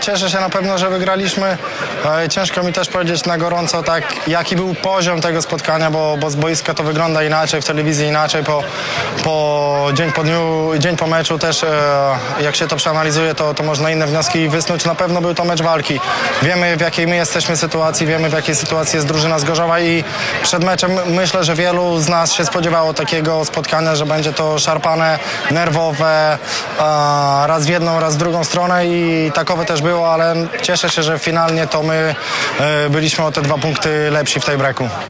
Pomeczowy komentarz